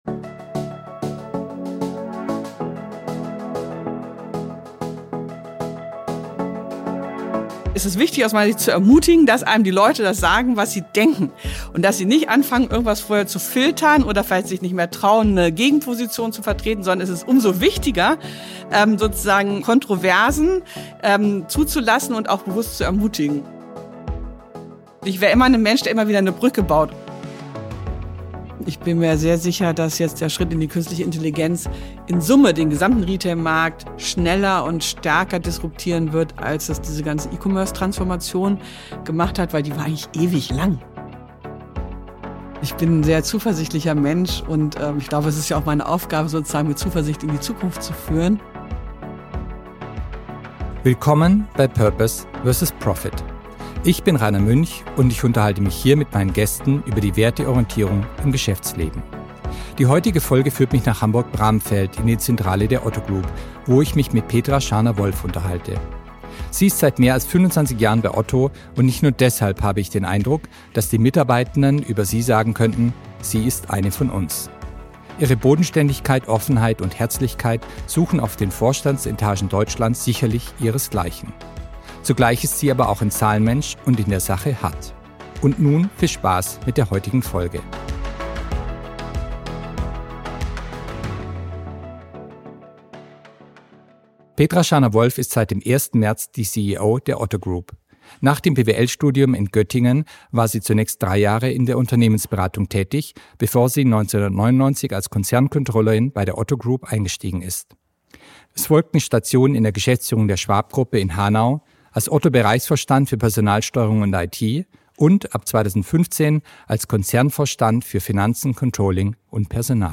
Ein inspirierendes Gespräch zu Purpose vs. Profit.